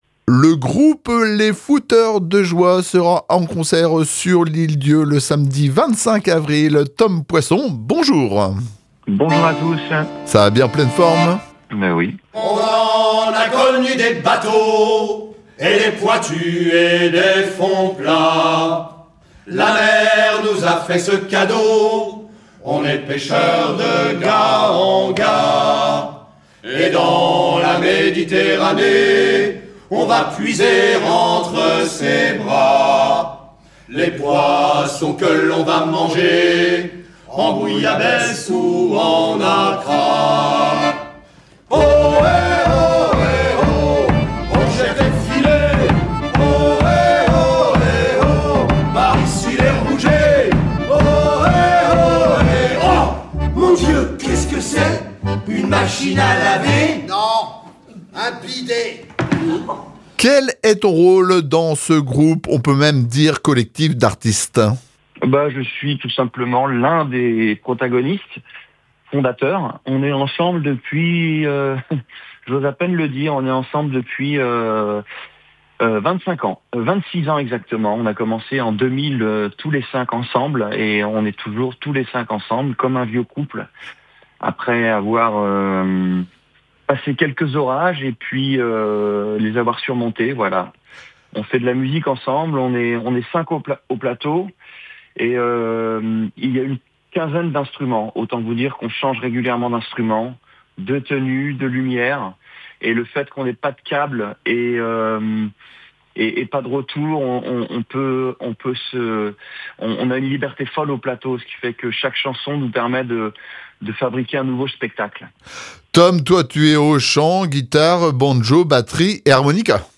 un des chanteurs de cette joyeuse troupe, nous présente l’univers du groupe et ce spectacle à la fois drôle, poétique et profondément humain.